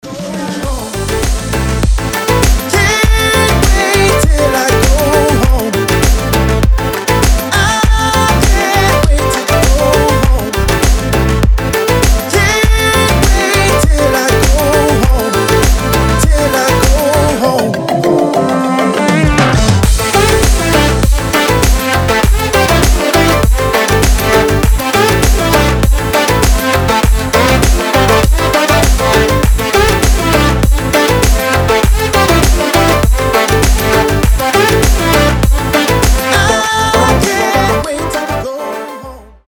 • Качество: 320, Stereo
мужской вокал
заводные
Dance Pop
house